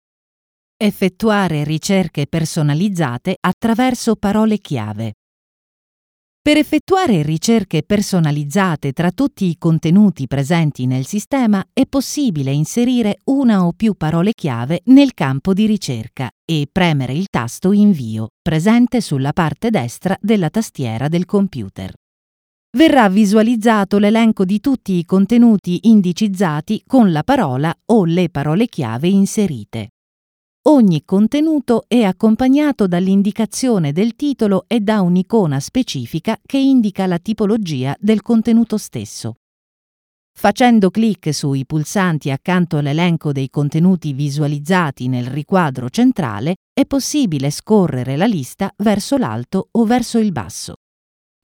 Natürlich, Vielseitig, Zuverlässig, Erwachsene, Sanft
E-learning